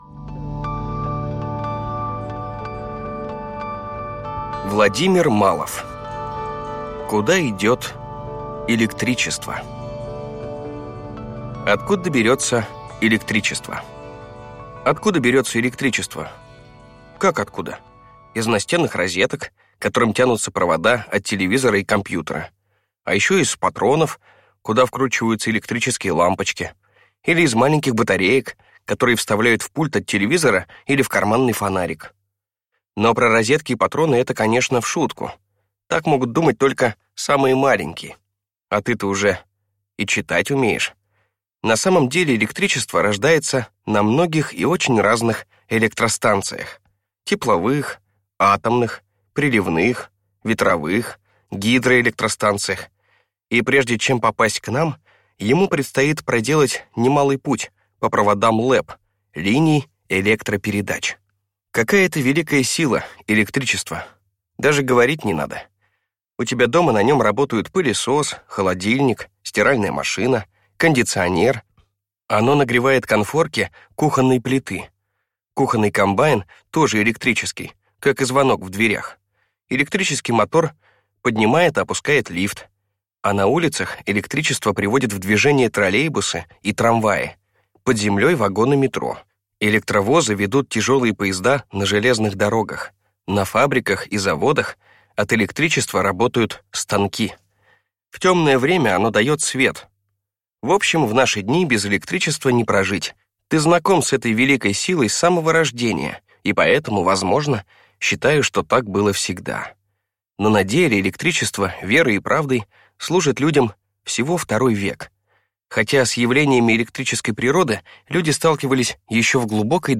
Аудиокнига Куда идёт электричество?